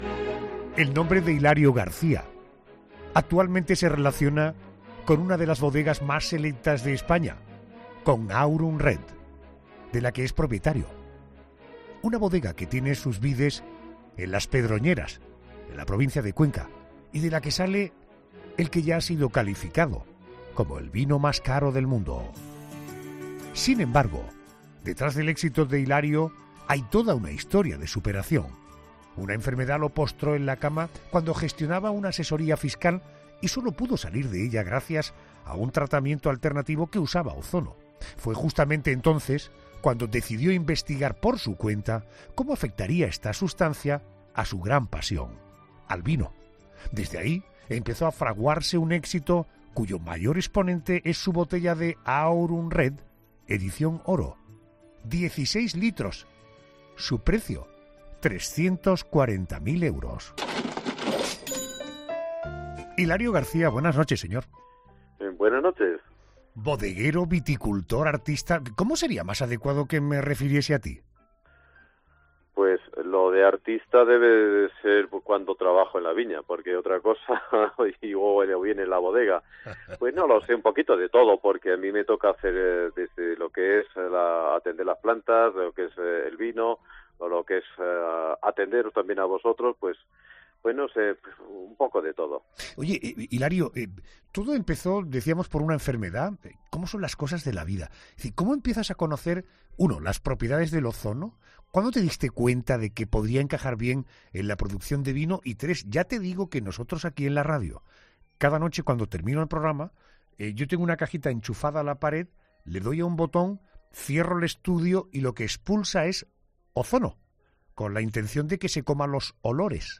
una entrevista en 'La Noche de COPE'